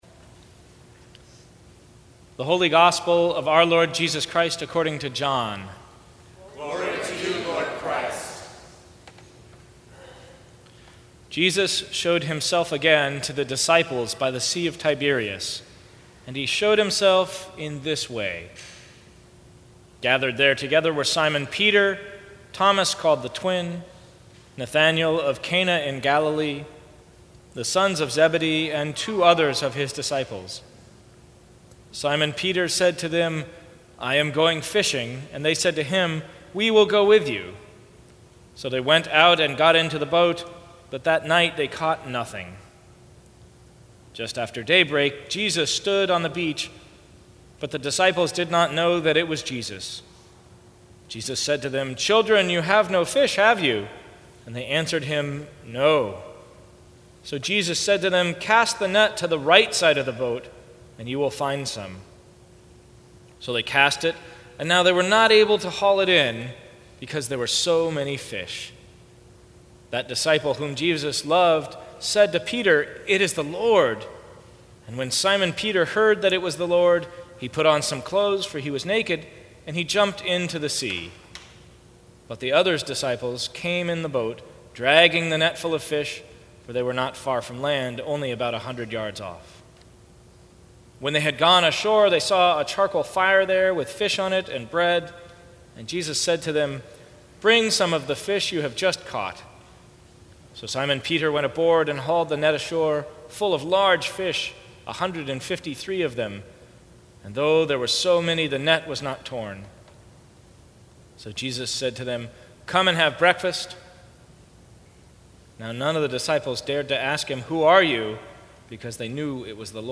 Sermons from St. Cross Episcopal Church Third Sunday of Easter Apr 10 2016 | 00:16:48 1x 00:00 / 00:16:48 Subscribe Share Apple Podcasts Spotify Overcast RSS Feed Share Link Embed